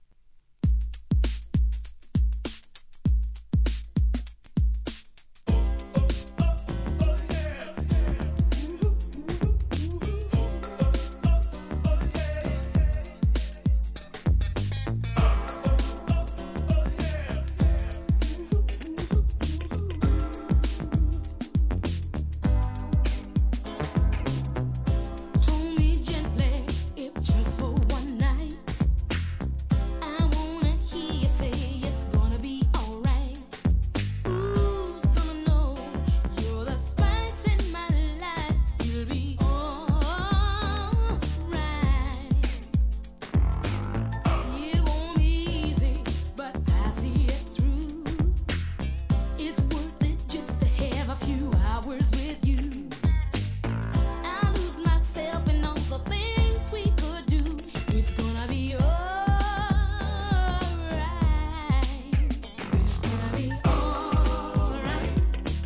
SOUL/FUNK/etc...